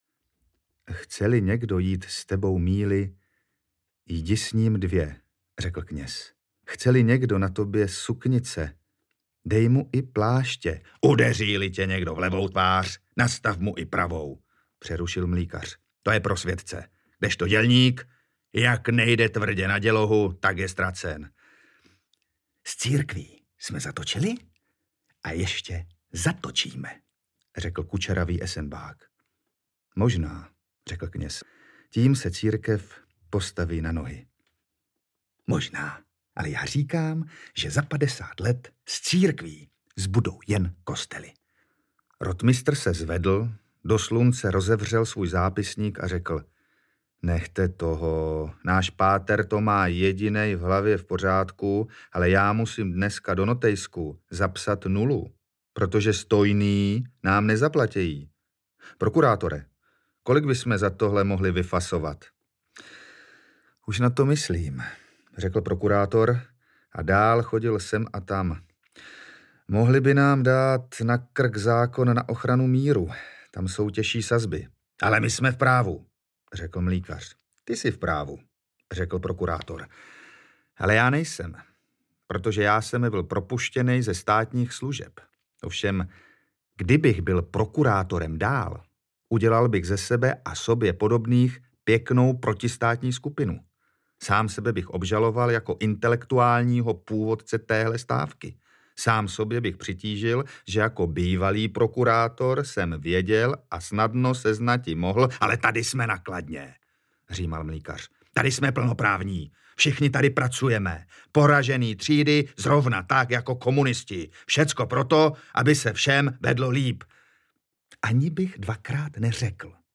Inzerát na dům, ve kterém už nechci bydlet audiokniha
Ukázka z knihy